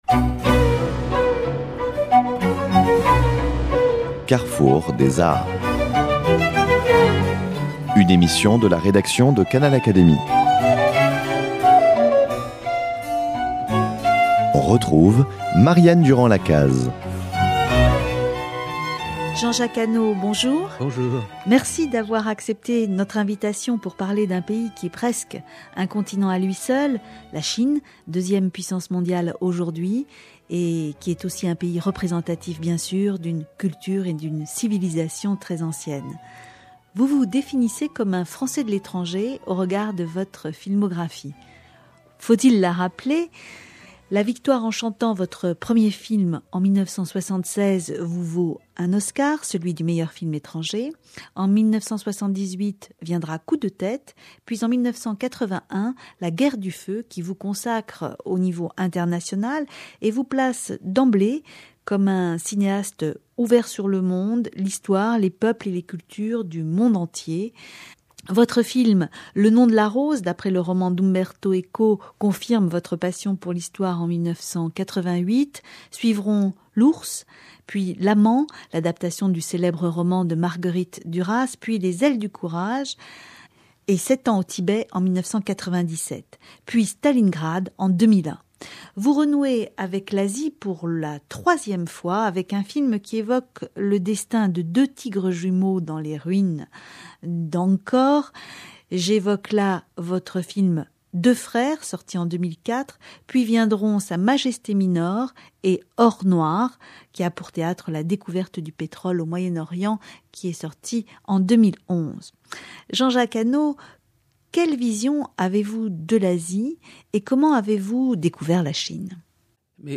Jean-Jacques Annaud évoque dans cette entretien la Chine, deuxième puissance mondiale aujourd’hui, représentative d’une culture et d’une civilisation très anciennes.